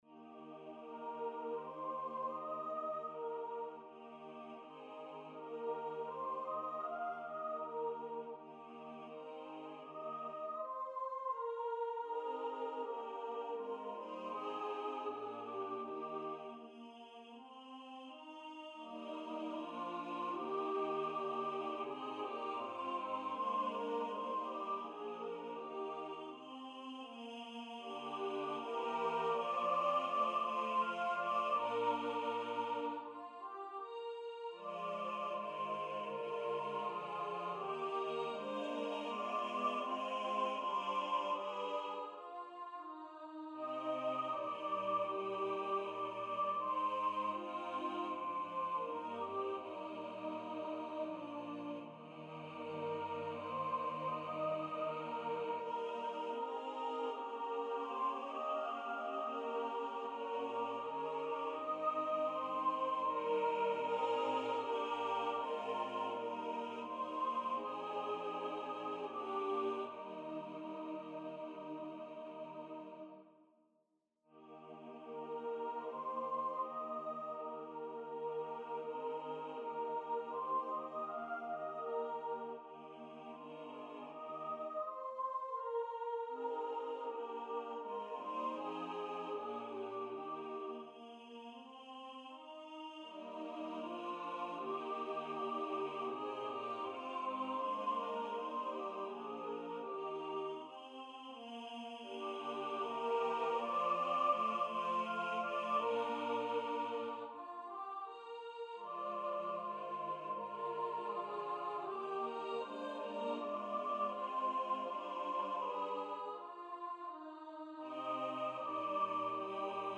Voices: SATB Instrumentation: Unaccompanied
Nights of Music - NotePerformer 4 mp3 Download/Play Audio